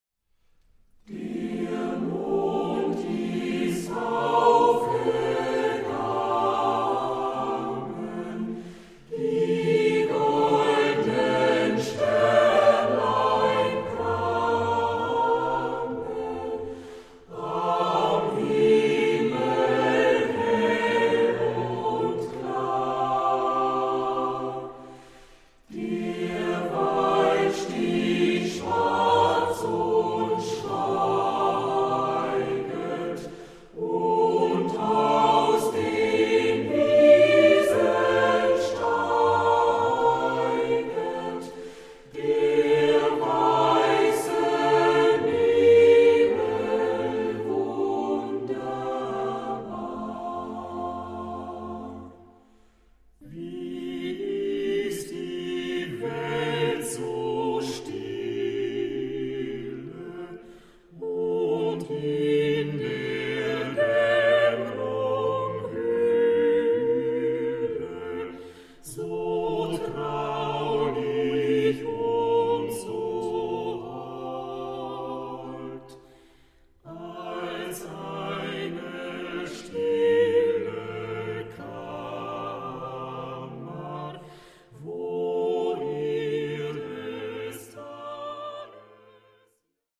• Sachgebiet: Chormusik/Evangeliumslieder
• Morgen und Abendlieder